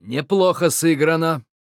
Нам удалось найти в клиенте фразы и локализованную озвучку Кадгара.
Локализованные фразы Кадгара
VO_HERO_08b_WELL_PLAYED_07.wav